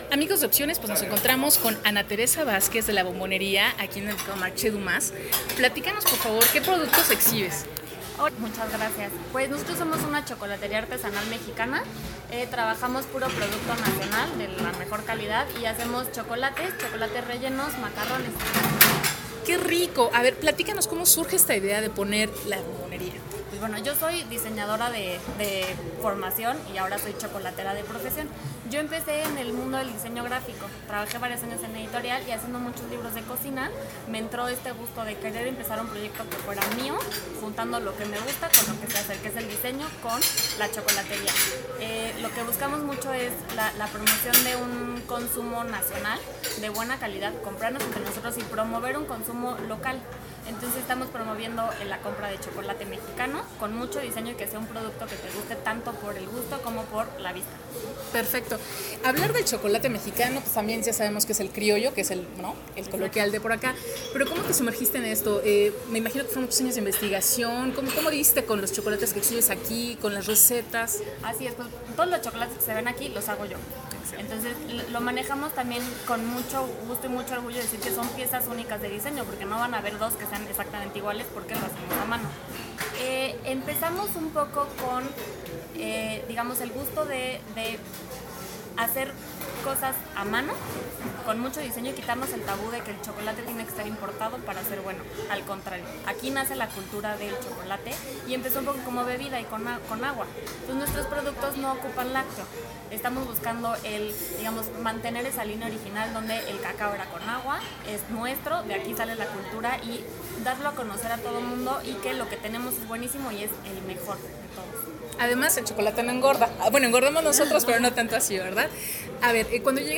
entrevista exclusiva